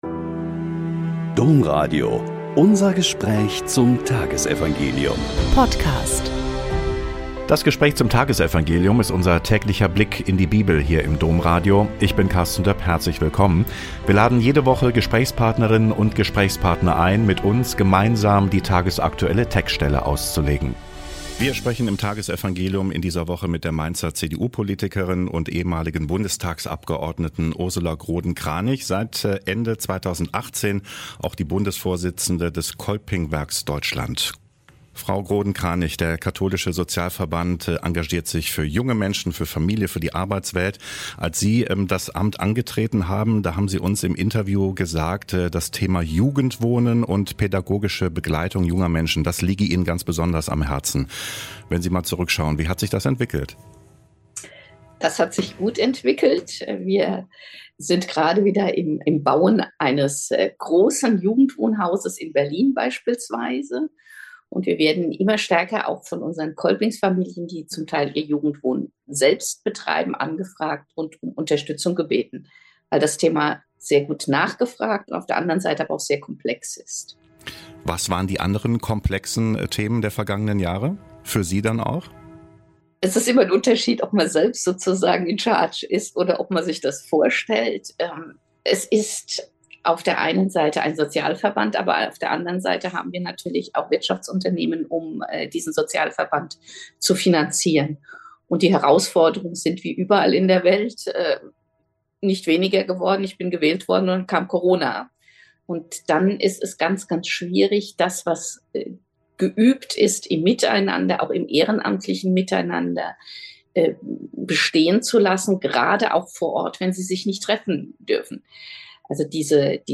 Joh 17,1-11a - Gespräch mit Ursula Groden-Kranich